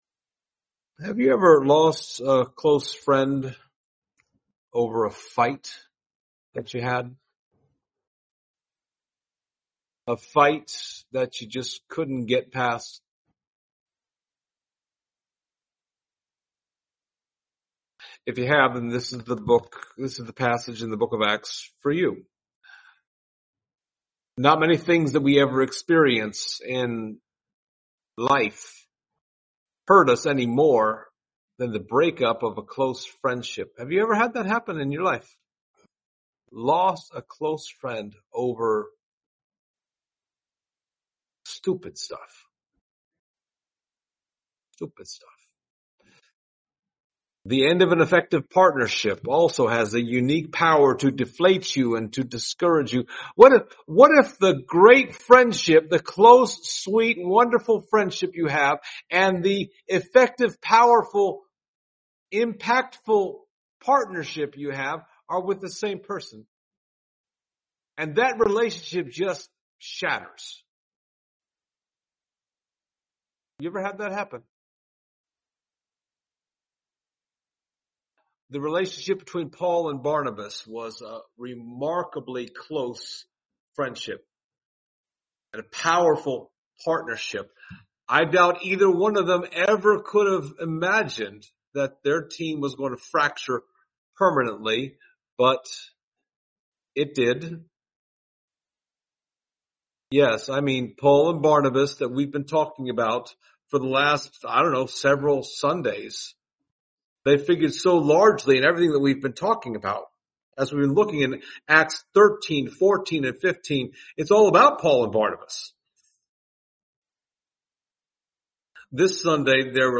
Passage: Acts 15:36-41 Service Type: Sunday Morning